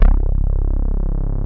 THINBASSC2-L.wav